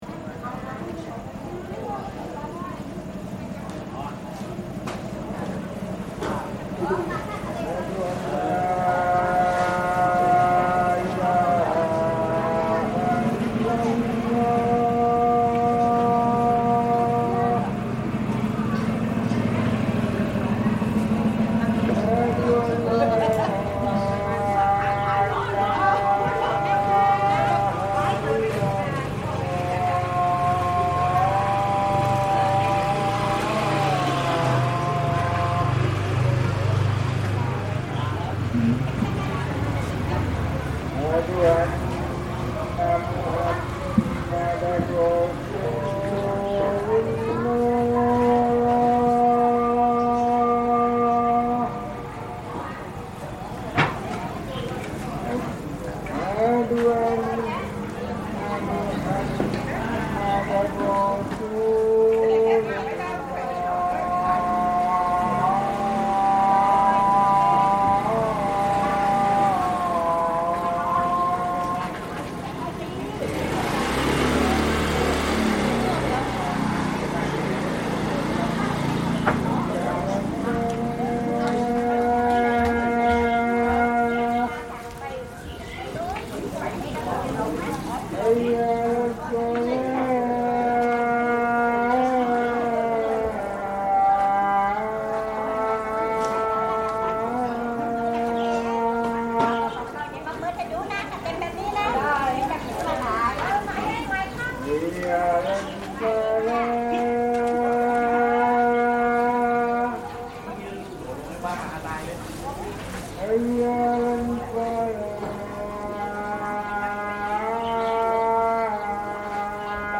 Call to prayer at the floating village
A call to prayer at the Bang Phat floating village in Thailand, known for its "floating" houses on stilts and a simple fishing community.